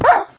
sounds / mob / wolf / hurt2.ogg
hurt2.ogg